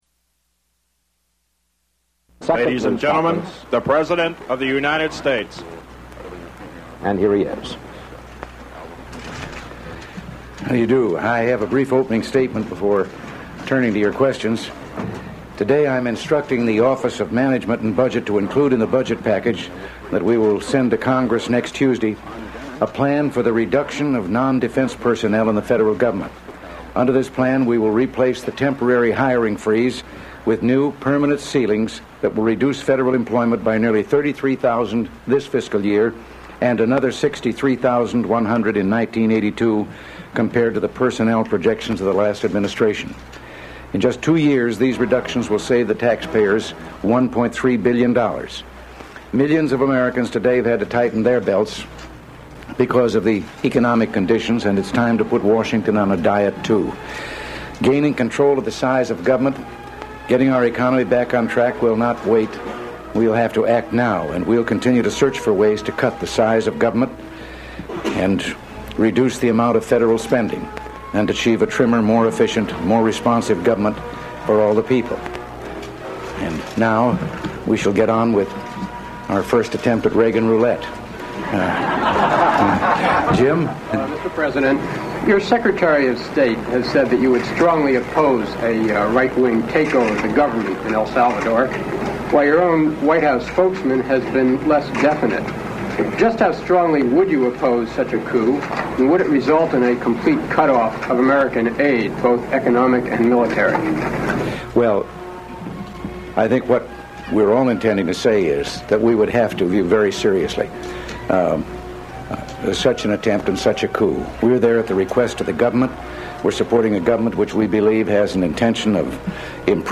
Press conference held by U.S. President Ronald Reagan
Ronald Reagan in a press conference. He announces a plan to reduce of non-defense personnel in the federal government and answers questions from reporters on foreign and domestic policy.
Broadcast 1981 March 6.